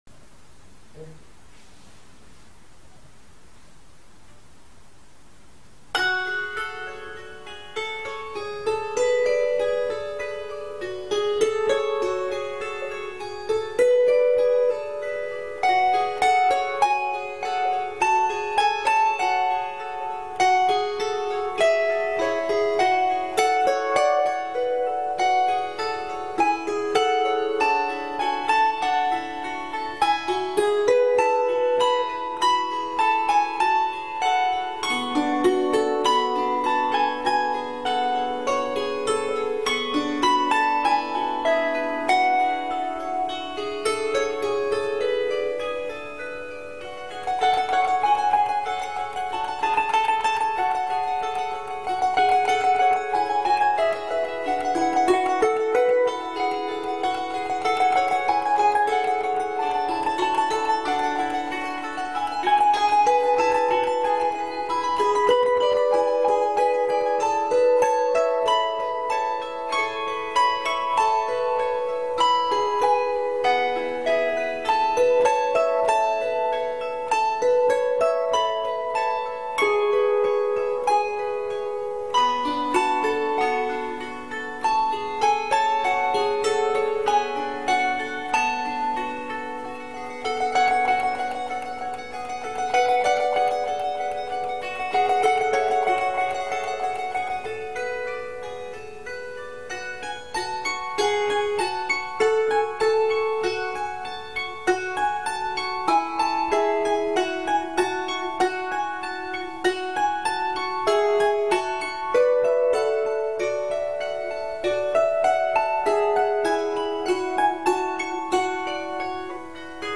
гуслей